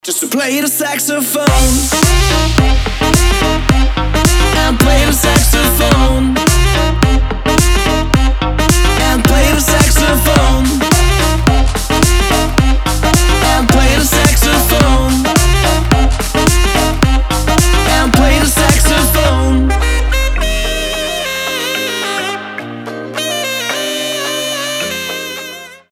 Зажигательная музыка со звучанием саксофона.